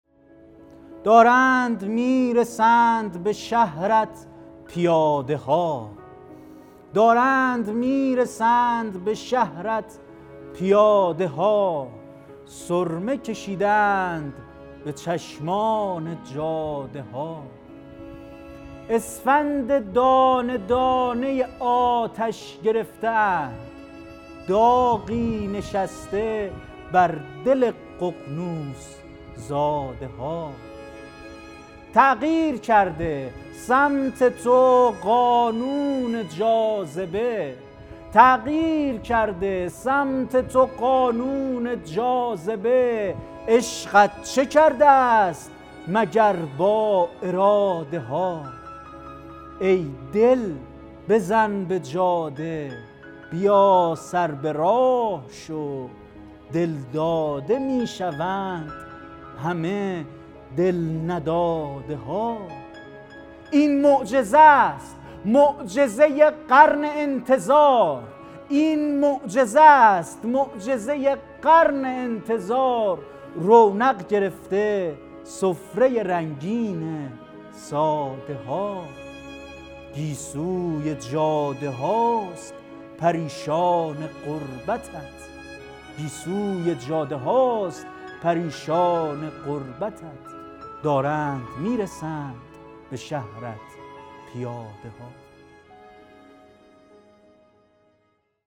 شعرخوانی | دارند می‌رسند به شهرت پیاده ها ...